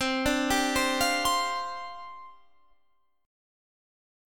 Csus2sus4 Chord